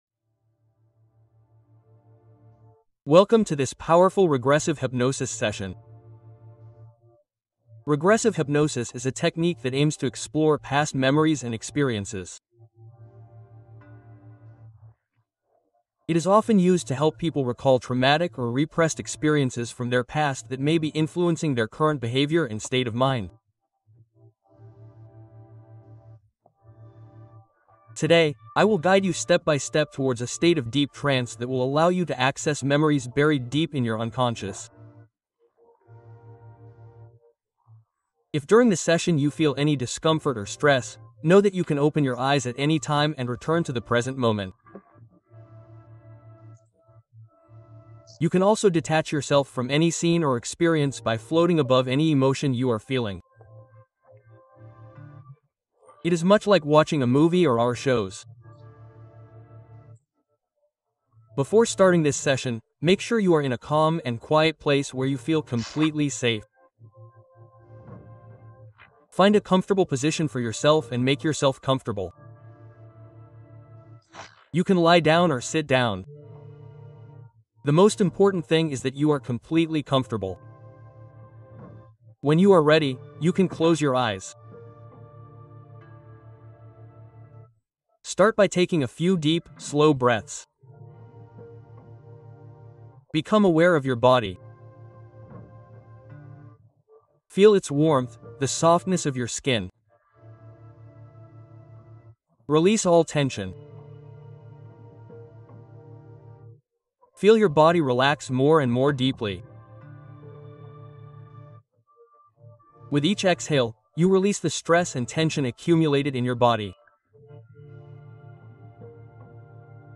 Hypnose profonde : lâcher-prise et paix totale